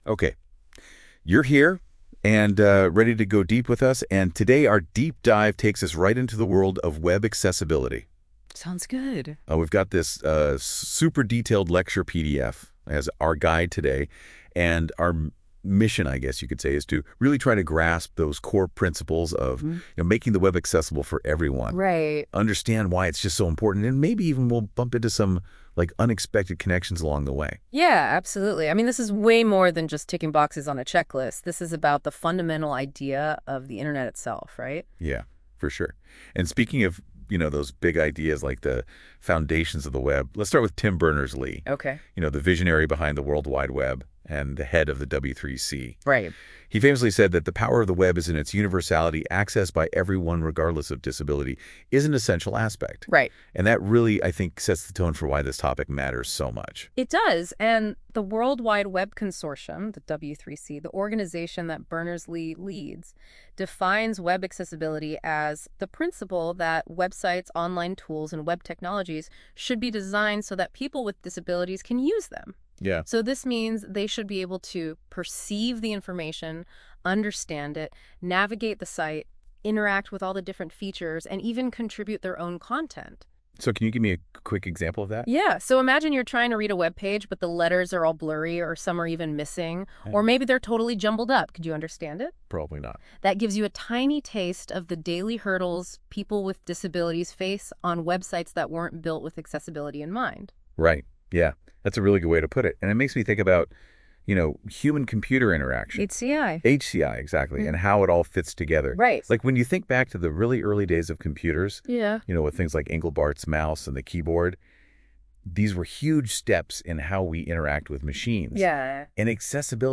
PODCAST on Week 8 Lecture – Web Accessibility CS-213 Spring 2025 (using Google's Notebook LLM)